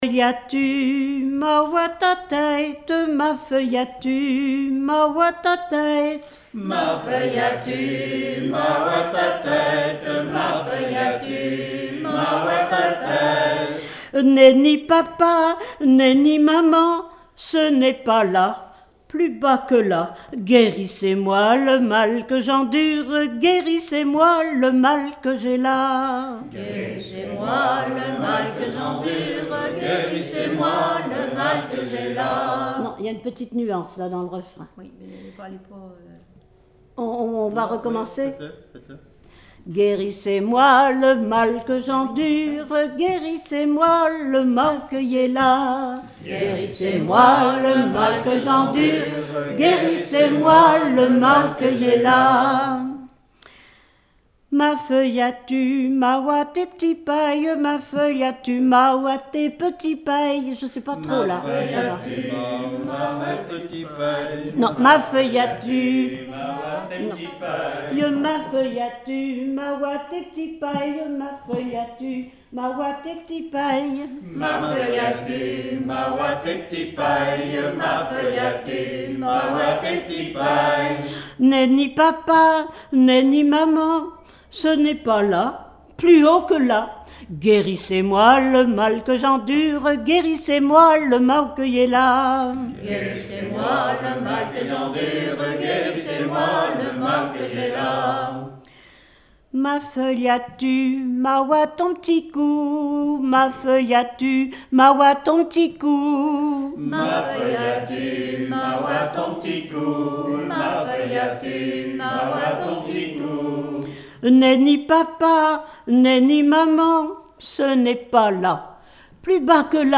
Genre énumérative
Chansons traditionnelles
Pièce musicale inédite